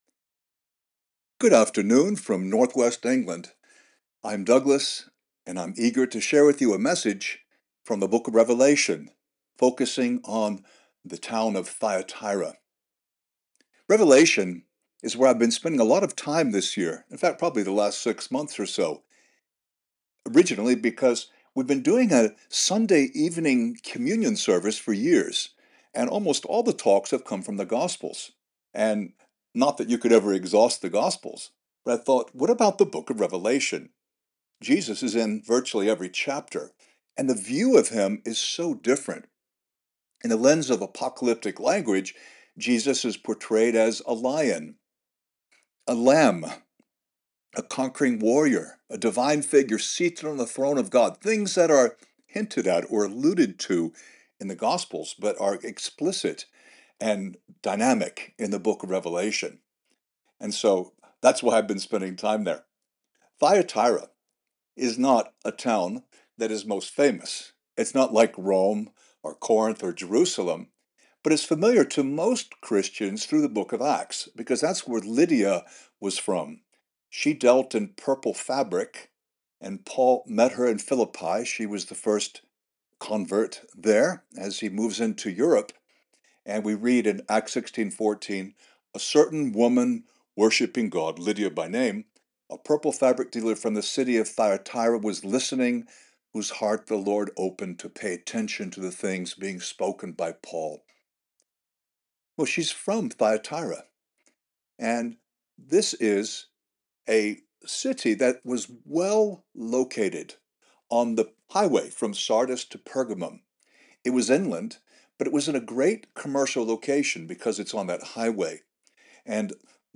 To hear the message (29 minutes), based on Revelation 2:18-29, please click on the arrow.